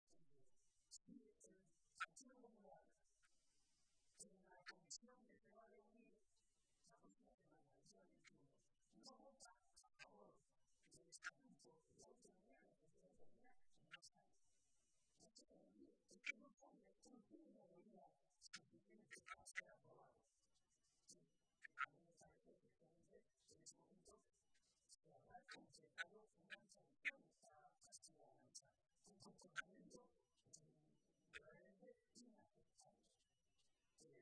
Momento de la clausura del Foro Joven de JSCM